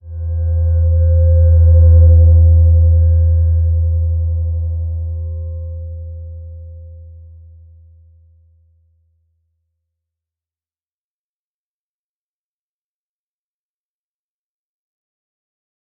Slow-Distant-Chime-E2-f.wav